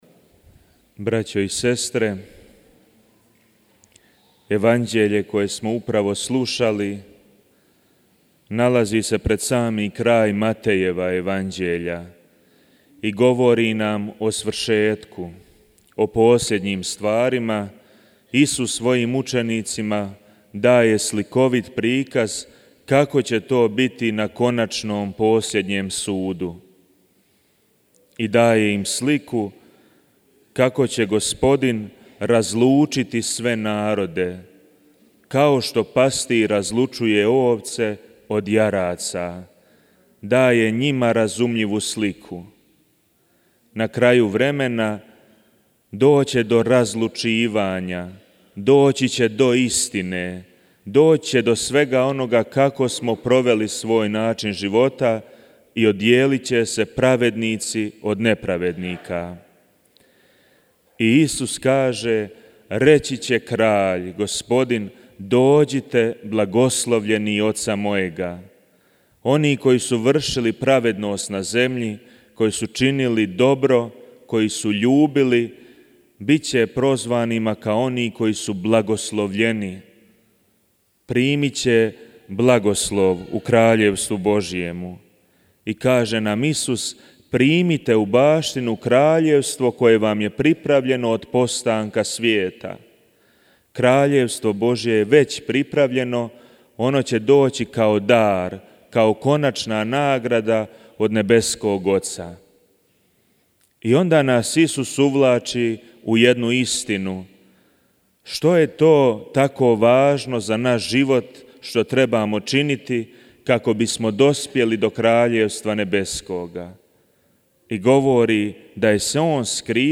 U svojoj homiliji